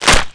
NECKSNAP3.WAV